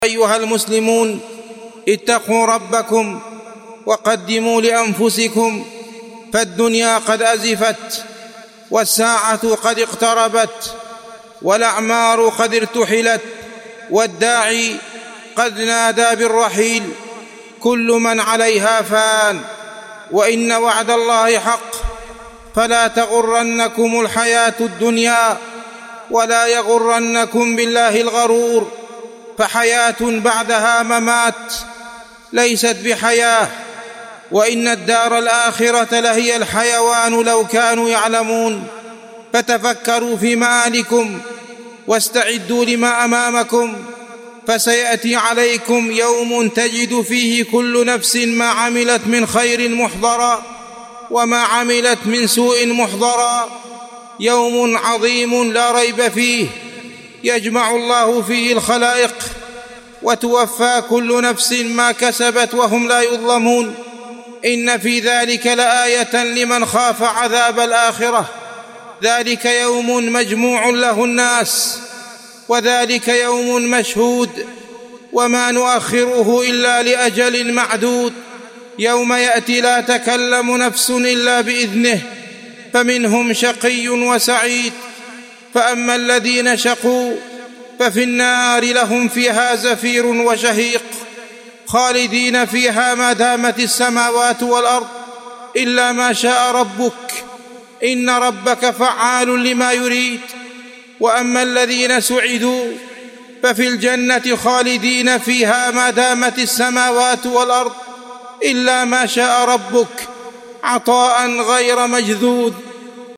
موعظة الاستعداد ليوم المعاد